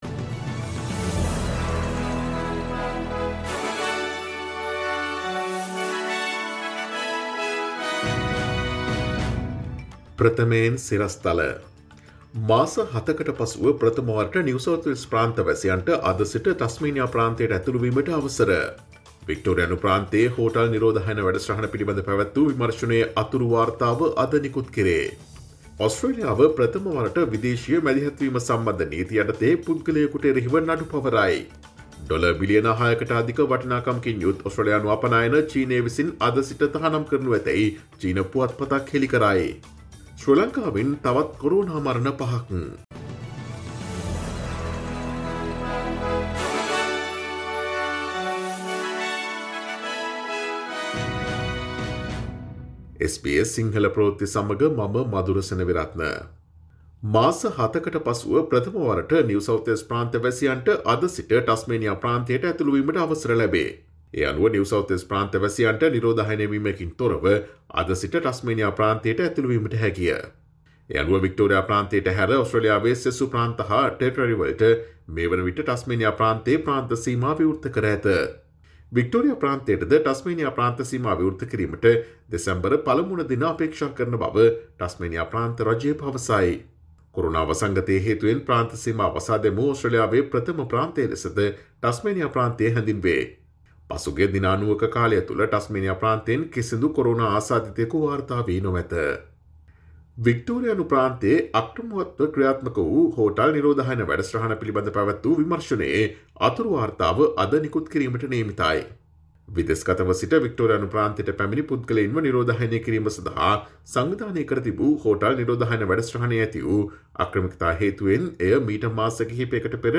Daily News bulletin of SBS Sinhala Service: Friday, 06 November 2020
Today’s news bulletin of SBS Sinhala radio – Friday, 06 November 2020.